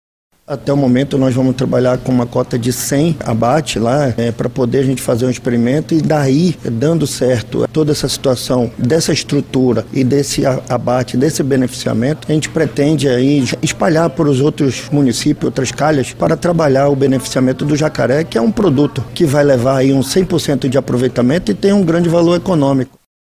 De acordo com o Instituto de Proteção Ambiental do Amazonas (IPAAM), responsável por emitir as licenças e a autorizações ambientais, a atividade já é realizada na reserva de desenvolvimento sustentável do Mamirauá, destaca o diretor-presidente Gustavo Picanço.